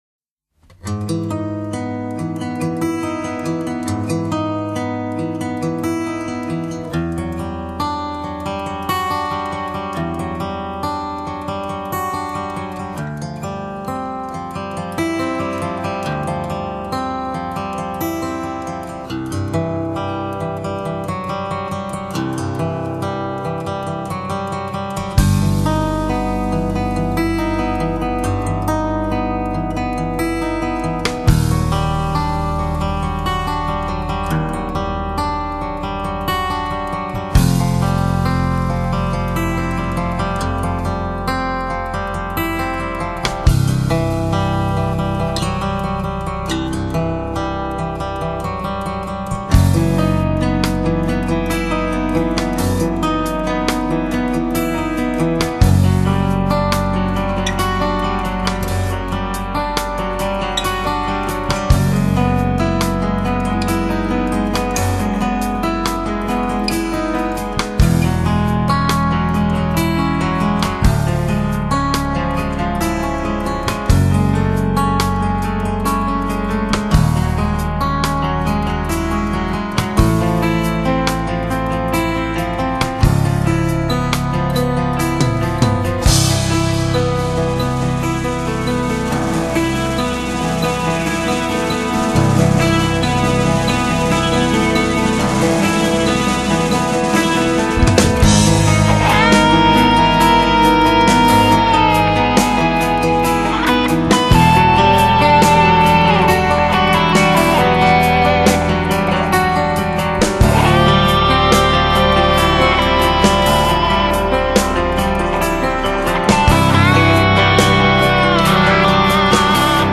音樂風格︰Jazz Fusion, New Age, Instrumental | 1CD |
guitars
keyboards
bass
saxophone
flute
drums